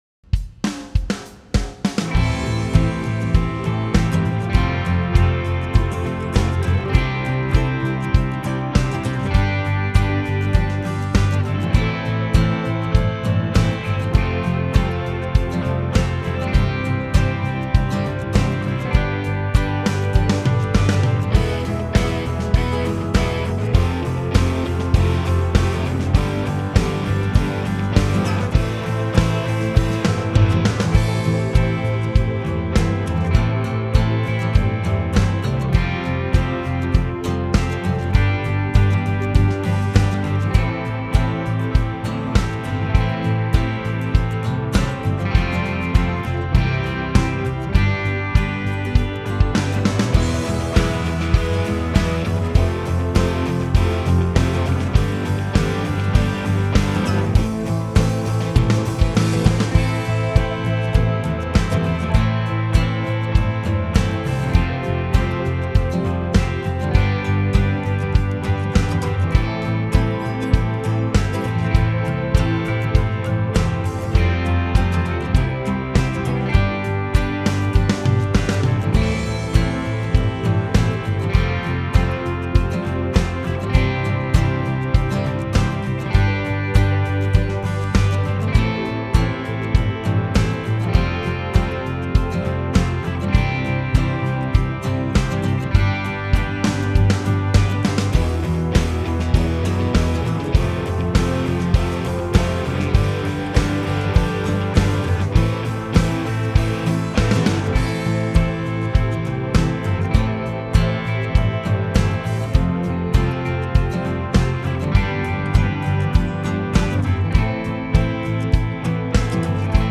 Home > Music > Rock > Bright > Chasing > Restless